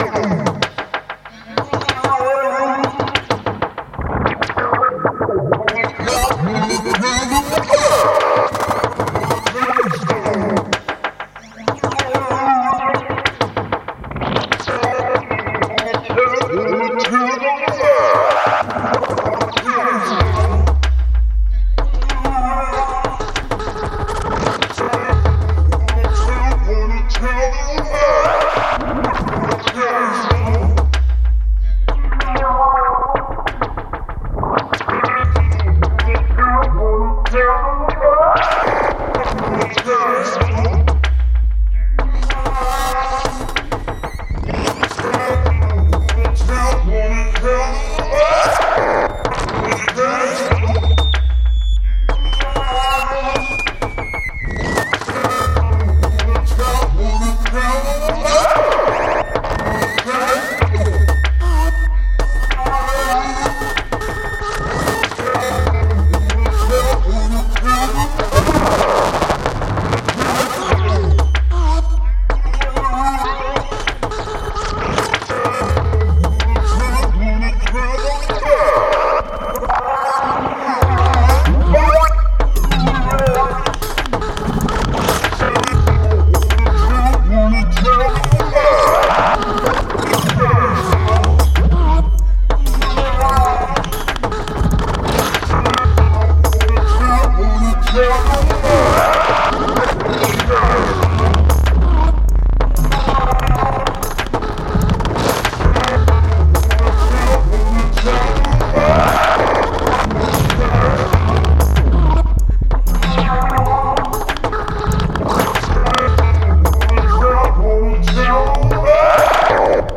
00:00:00 Tempo: 95 Taktart: 4/4 Länge